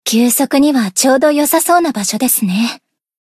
贡献 ） 分类:蔚蓝档案语音 协议:Copyright 您不可以覆盖此文件。
BA_V_Sena_Cafe_Monolog_3.ogg